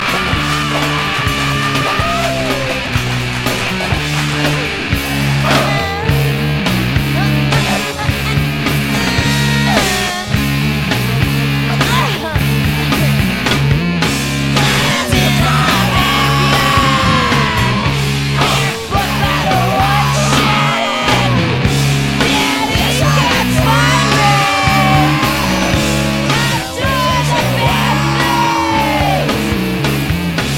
noise music collective